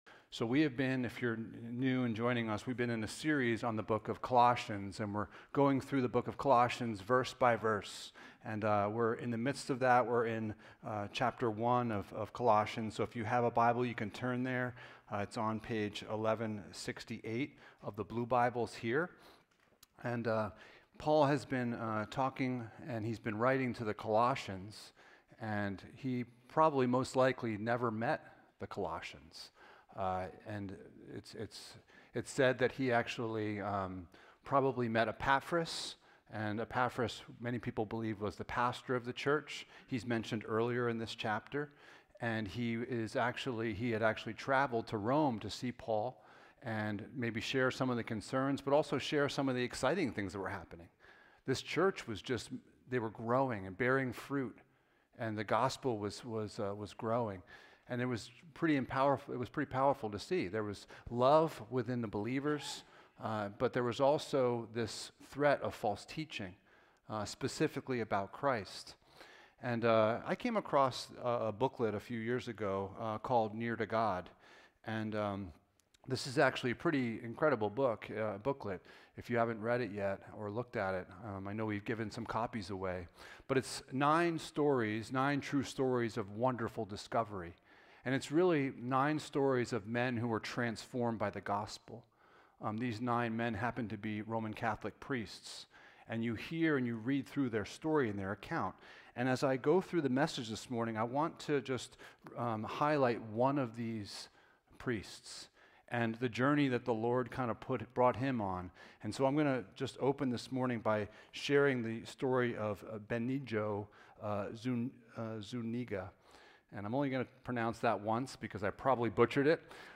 The Supremacy of Christ Passage: Colossians 1:21-23 Service Type: Sunday Morning « Why It’s Essential to Get the Biblical Jesus Right